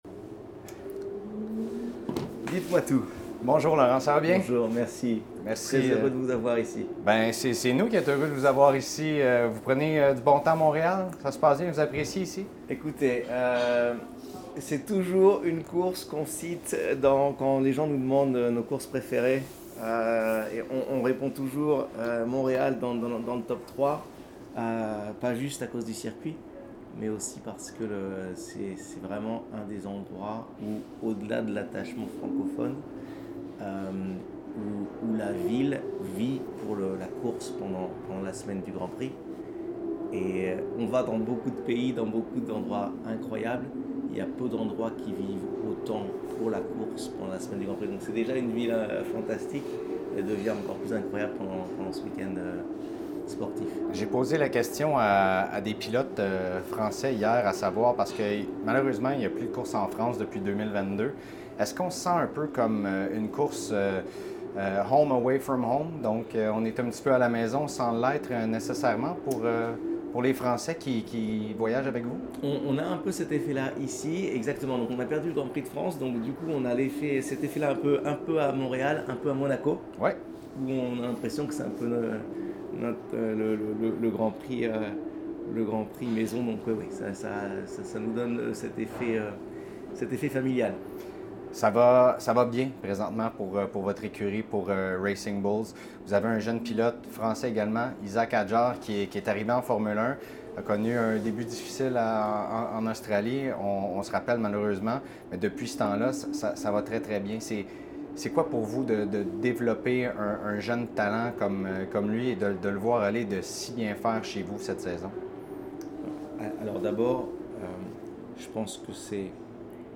Entretien avec Laurent Mekies de Visa Cash App Racing Bulls